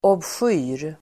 Ladda ner uttalet
Uttal: [obsk'y:r]